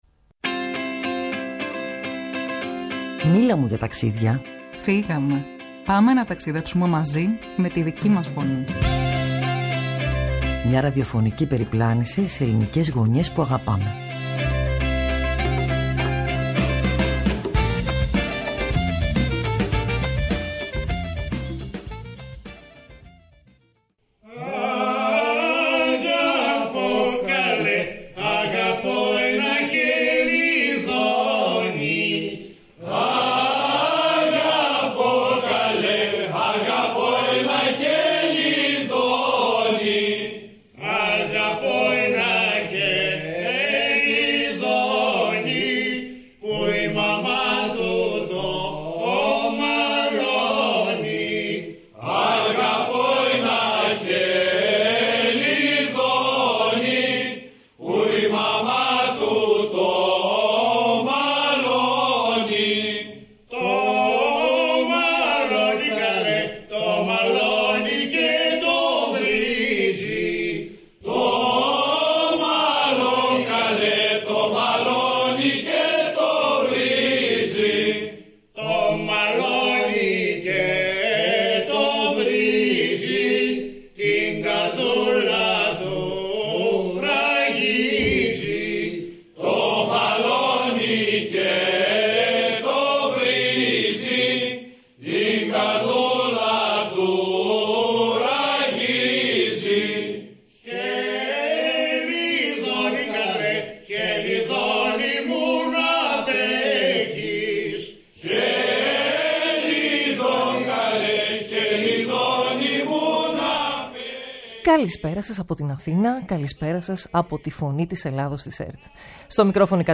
Στην εκπομπή μίλησαν: Πολυτίμη Φαρμάκη, Αντιπεριφερειάρχης Τουρισμού, Πολιτισμού & Περιβάλλοντος — για τη βιωσιμότητα, τους φυσικούς πόρους, τον τουρισμό και την καθημερινότητα μιας περιοχής που αναπνέει μέσα στην αλλαγή.